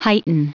Prononciation du mot heighten en anglais (fichier audio)
Prononciation du mot : heighten